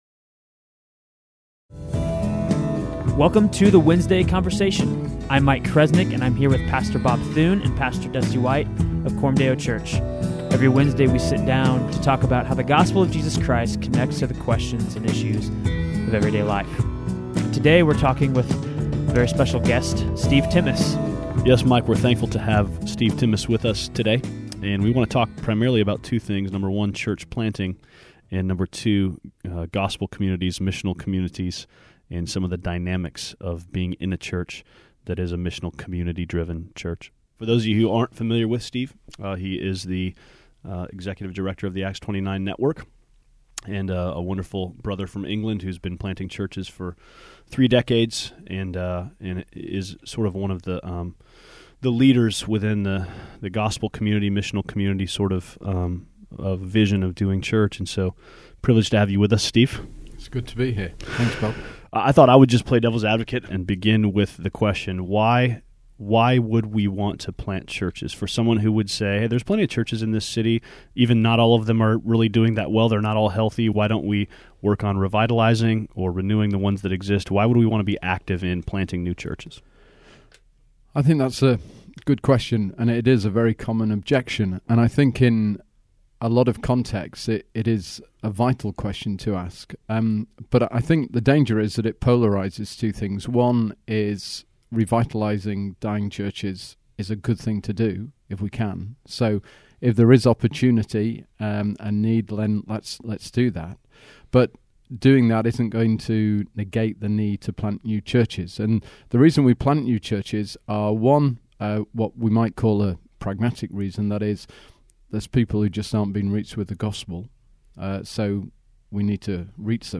in the studio with us